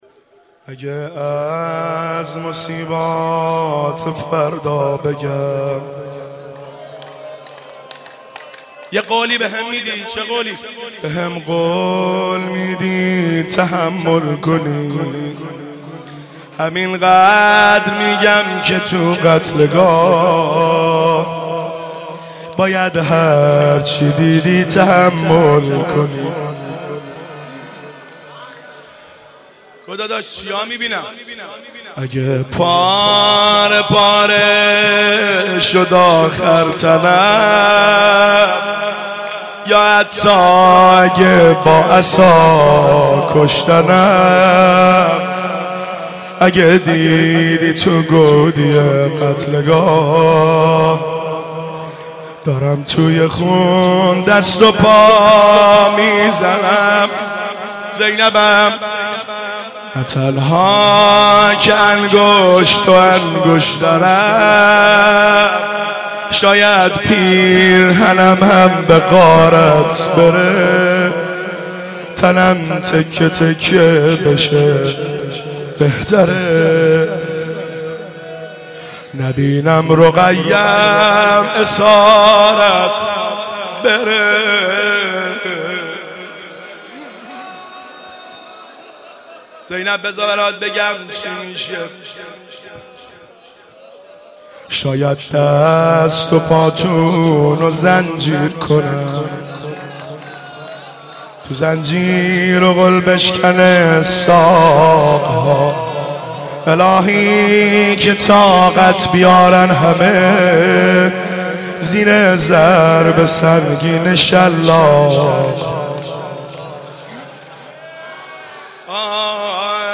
عقیق:شب عاشورا محرم95/هیئت غریب مدینه امیر کلا(بابل)
زمزمه/اگه از مصیبت